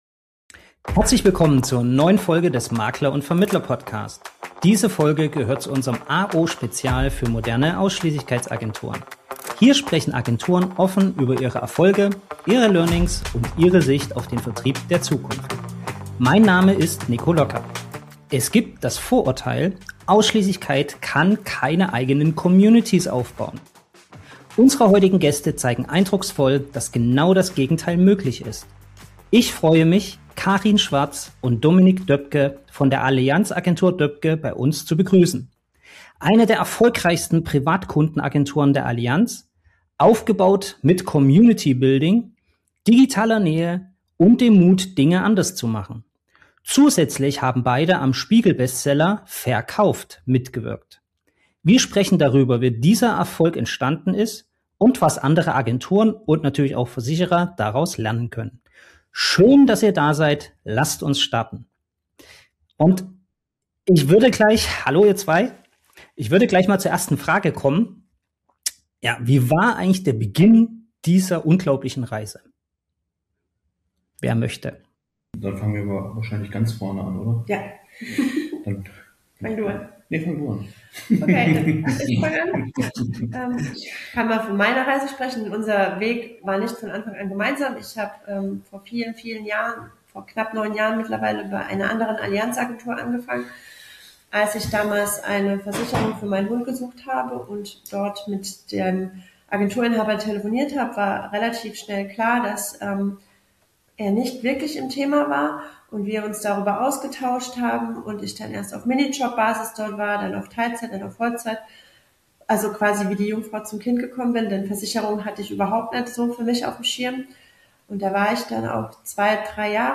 Im Gespräch erzählen sie von ihren Anfängen, dem Umgang mit Skepsis innerhalb der Branche und den Erfolgsfaktoren hinter ihren beeindruckenden Communities.